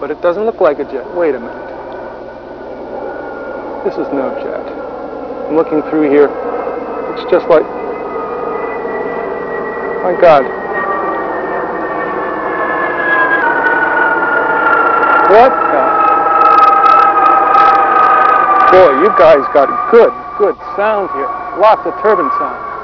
A Jet-like sound was played in reverse as Manta Ray approached camera.
Note mid-point where camera jammed and sound volume is missing.
Note the sudden drop in volume (top of graph) just before the mid-point (mid).
Frequency diagram of jet-like sound, which shows typical noise and fluctuations coming from a commercial jetliner, but lacks the highest frequencies (possibly filtered out before the sound was reversed and broadcast from the Manta Ray).